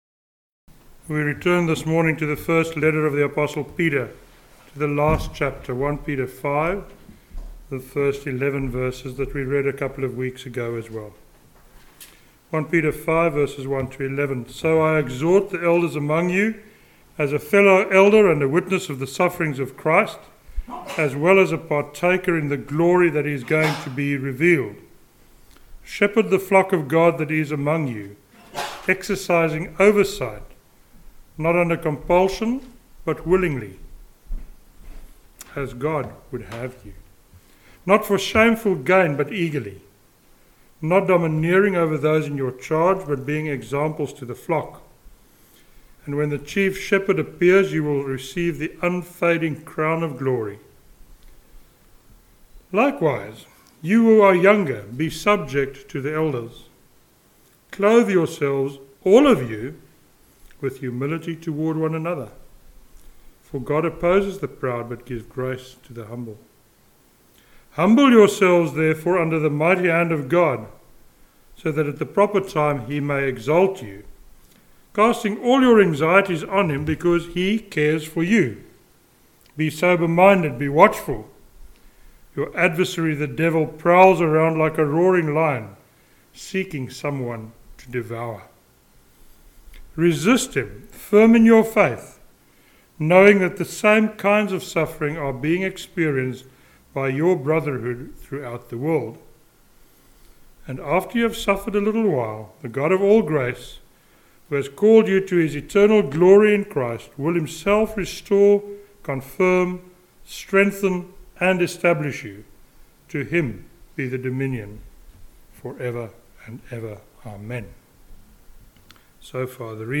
The sermon today, as you may have guessed from the sermon title, has to do with humility.